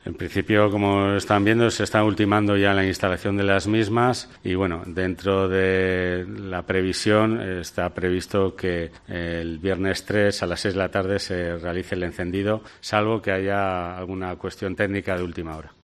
Kilian Cruz-Dunne, portavoz del Ayuntamiento de Logroño